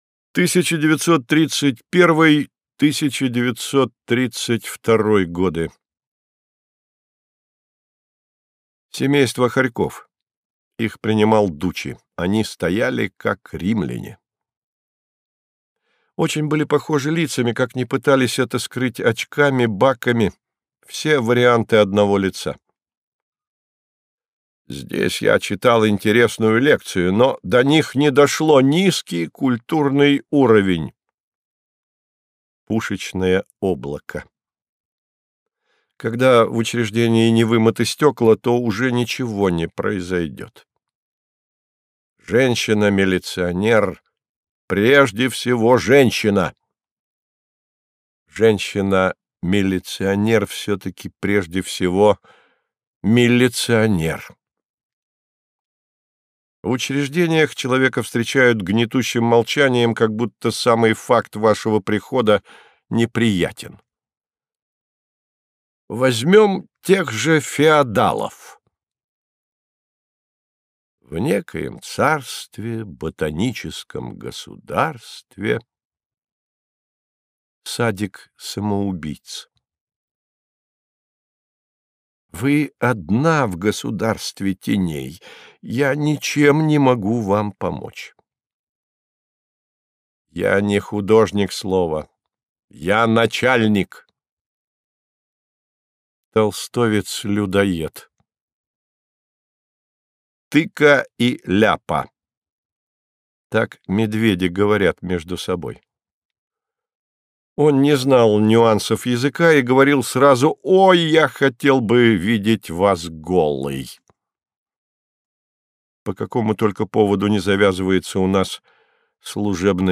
Aудиокнига Из записных книжек 1925-1937 гг.